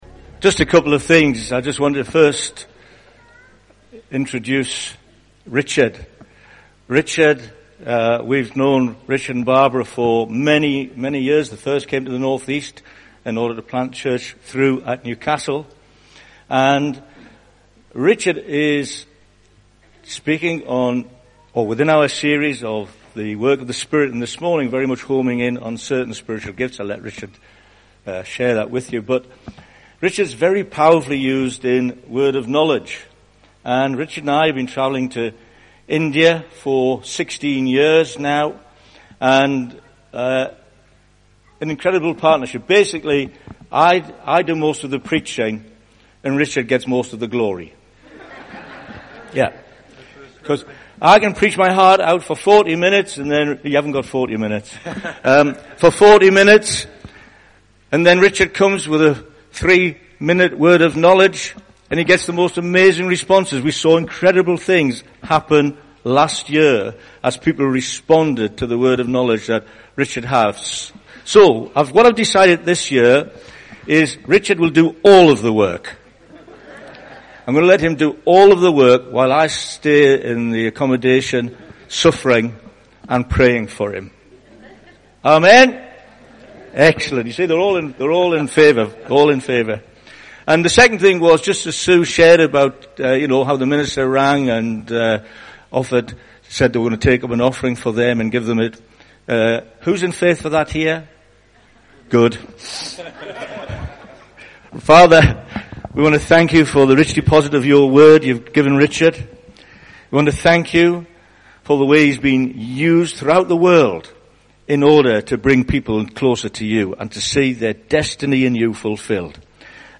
A sermon series from Emmanuel Church from summer 2016.